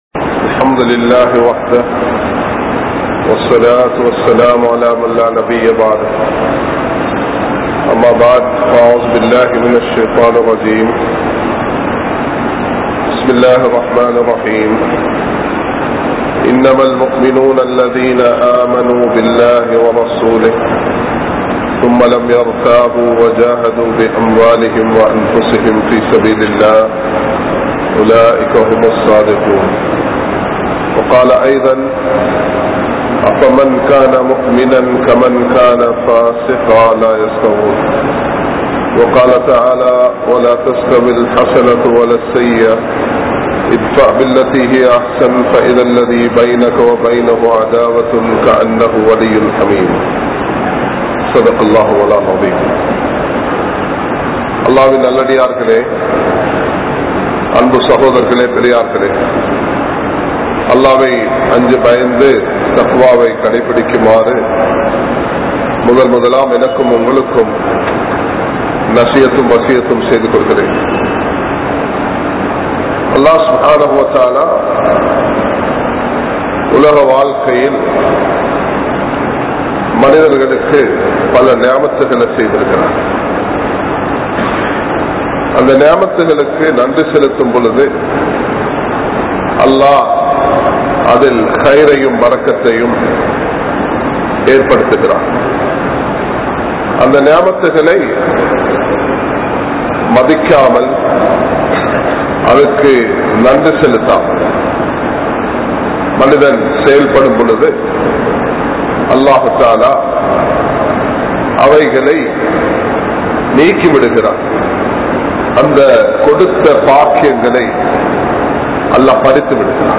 Naattil Amaithiyai Eatpaduthugal(Bring peace to the country) | Audio Bayans | All Ceylon Muslim Youth Community | Addalaichenai
Colombo 03, Kollupitty Jumua Masjith